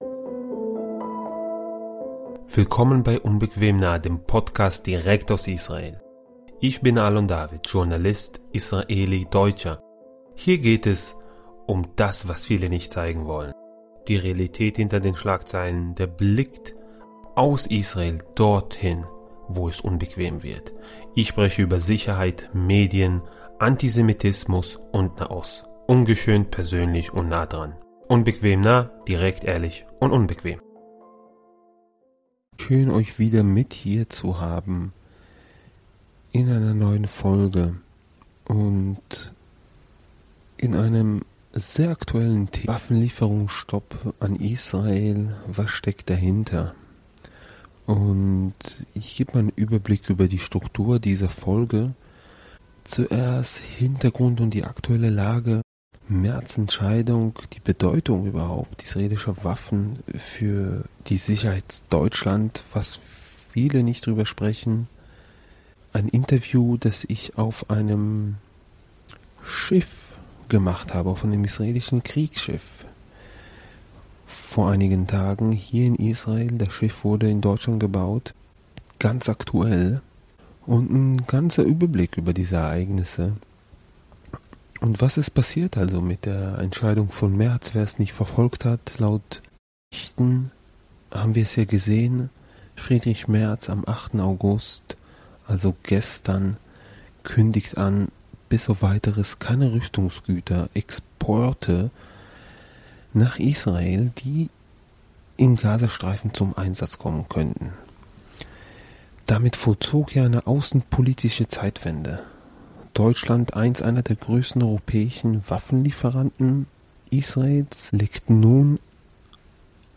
Exklusiv hört ihr mein Interview an Bord eines israelischen Kriegsschiffs der Saʿar-6-Klasse, gebaut in Deutschland und ausgestattet mit modernster israelischer Technologie. Der Offizier berichtet, wie das Schiff über 20 Drohnen abgefangen hat - Angriffe, die sonst Zivilisten und strategische Infrastruktur getroffen hätten. Eine Folge über militärische Realität, politische Symbolik und die Frage: Wer schützt hier eigentlich wen?